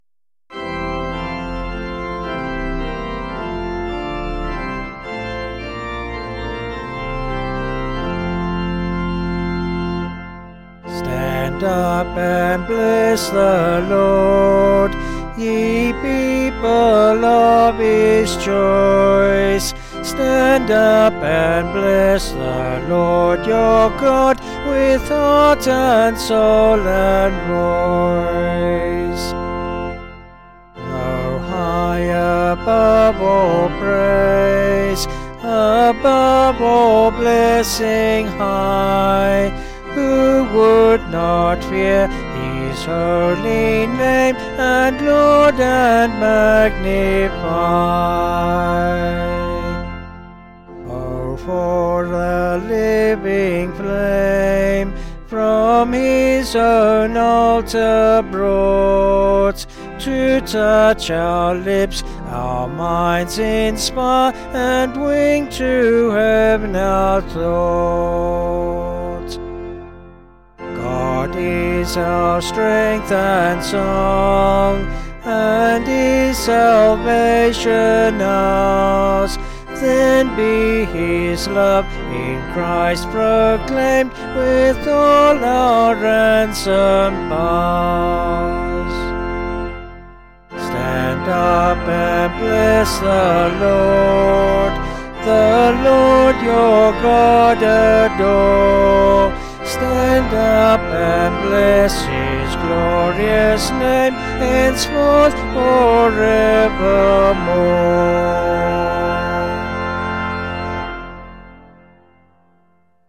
Vocals and Organ   262.8kb Sung Lyrics